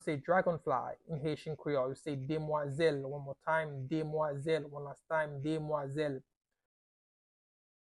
Listen to and watch “Demwazèl” audio pronunciation in Haitian Creole by a native Haitian  in the video below:
How-to-say-Dragonfly-in-Haitian-Creole-Demwazel-pronunciation-by-a-Haitian-teacher.mp3